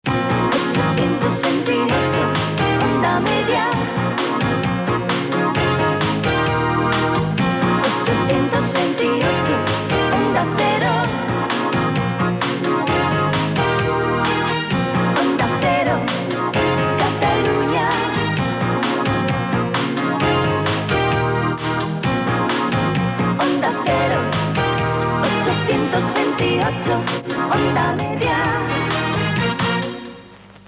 Indicatiu de l'emissora i freqüència